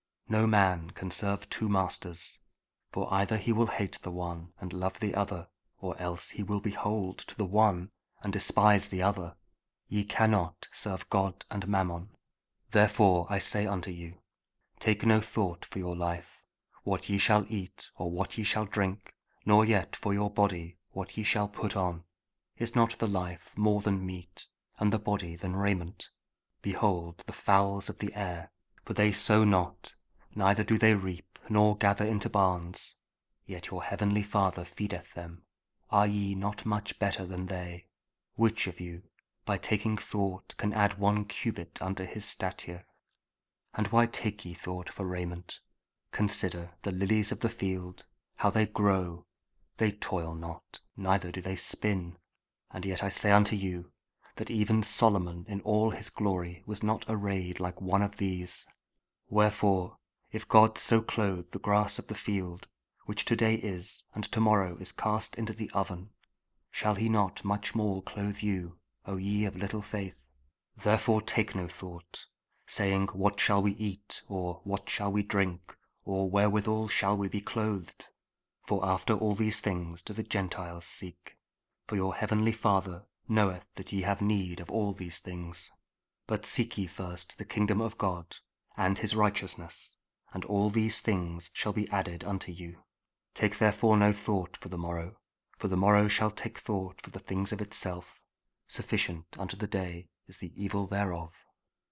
Matthew 6: 24-34 – Week 11 Ordinary Time, Saturday (Audio Bible KJV, Spoken Word)